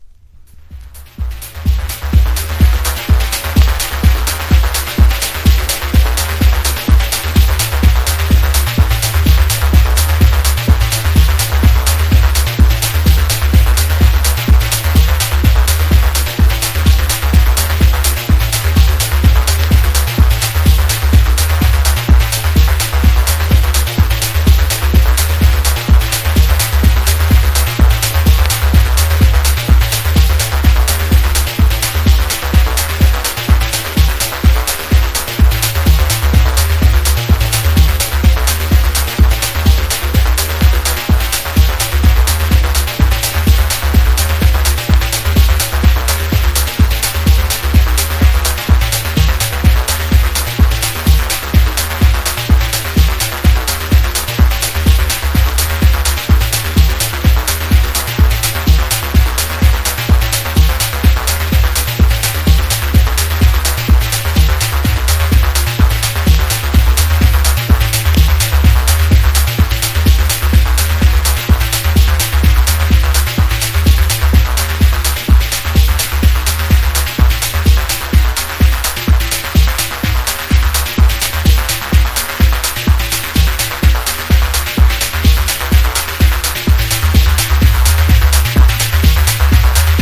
TECHNO / DETROIT / CHICAGO# DUB / LEFTFIELD